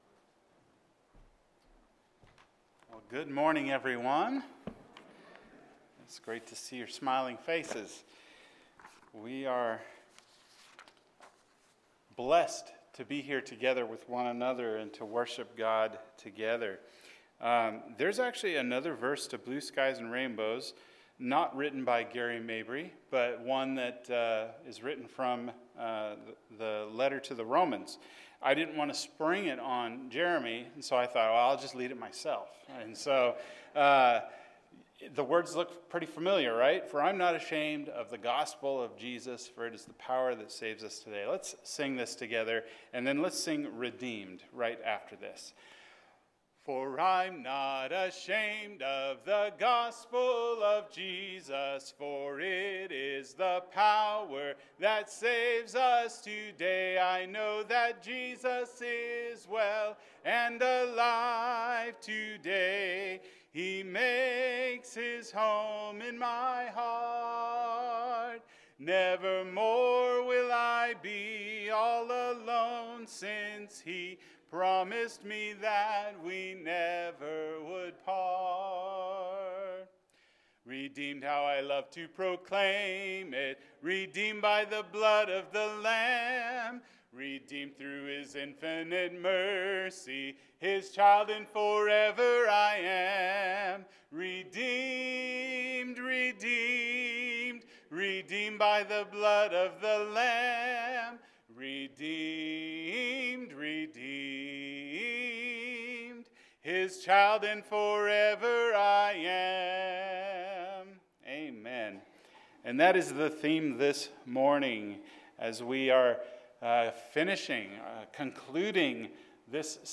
The Cross Leads to Salvation – Acts 2:36-41 – Sermon